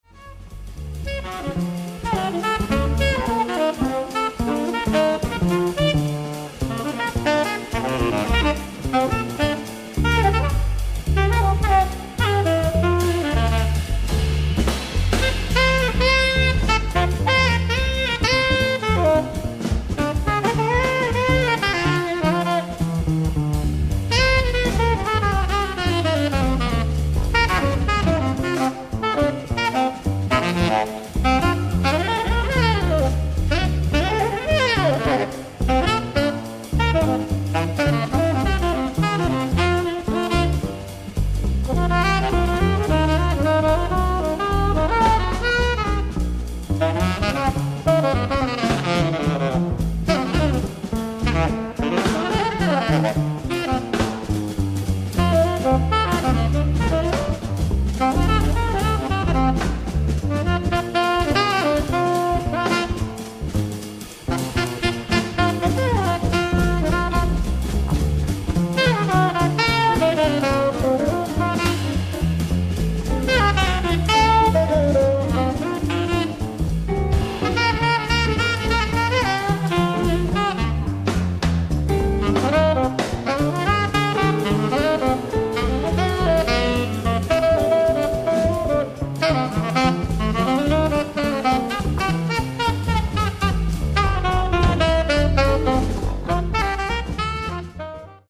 ライブ・アット・ジャズ・ミデルハイム、アントワープ、ベルギー
※試聴用に実際より音質を落としています。